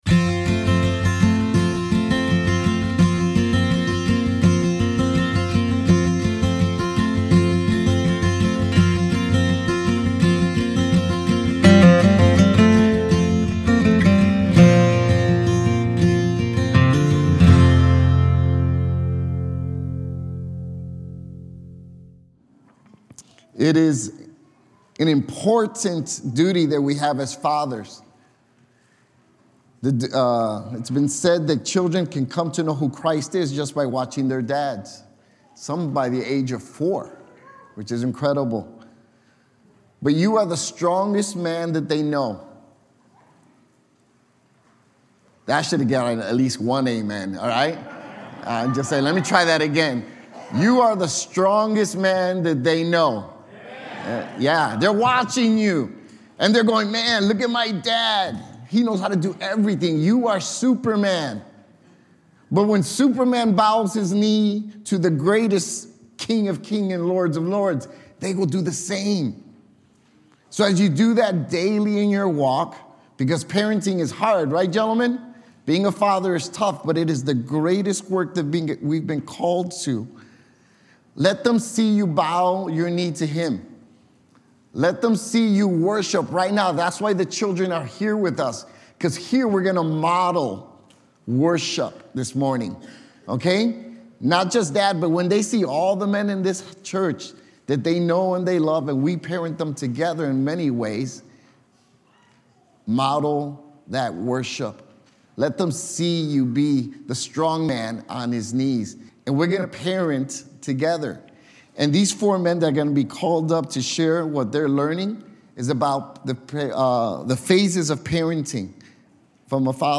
This Father's Day, we welcomed four men from our congregation to share on different phases of fatherhood: caregiver (birth to age 5), cop (ages 6-11), coach (ages 12-18), and consultant (age 19+). In the caregiver phase, fathers provide for basic needs and first experiences, reflecting God's provision.